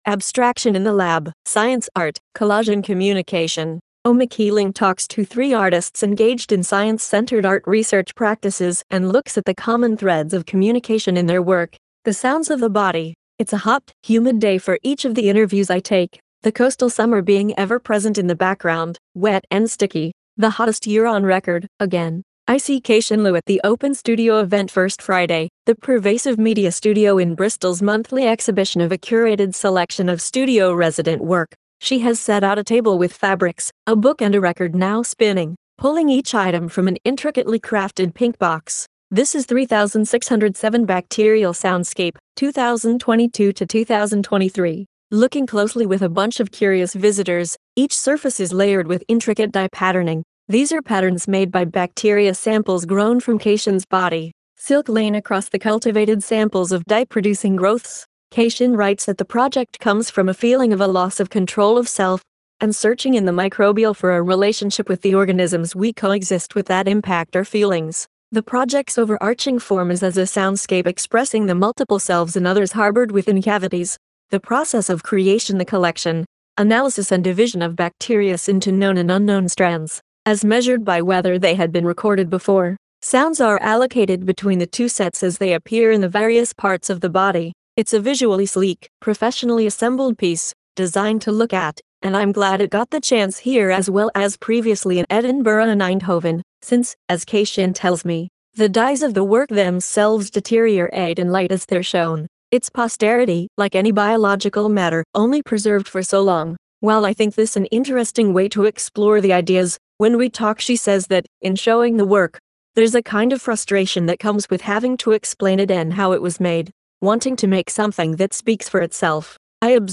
interviews three artists engaged in laboratory centred art research practices